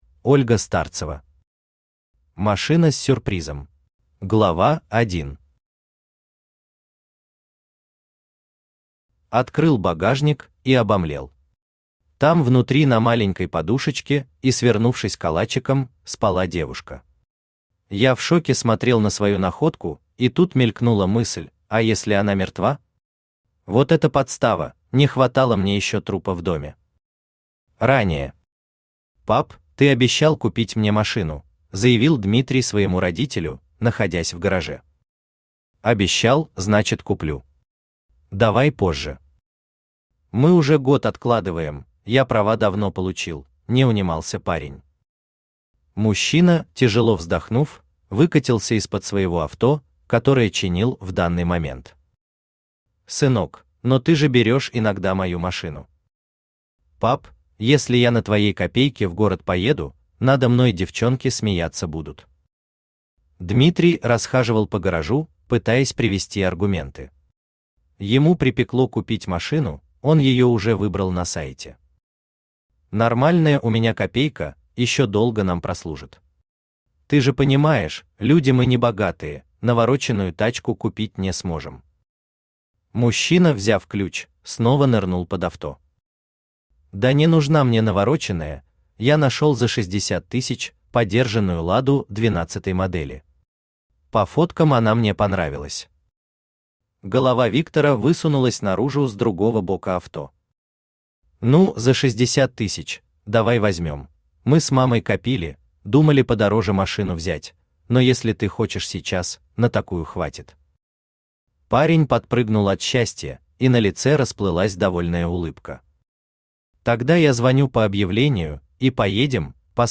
Аудиокнига Машина с сюрпризом | Библиотека аудиокниг
Aудиокнига Машина с сюрпризом Автор Ольга Старцева Читает аудиокнигу Авточтец ЛитРес.